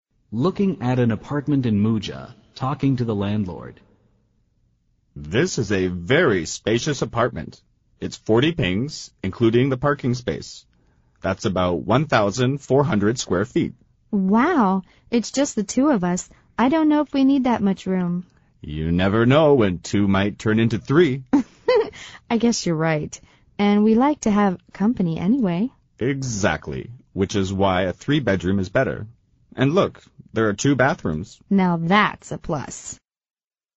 Looking at an apartment in Mucha, talking to the landlord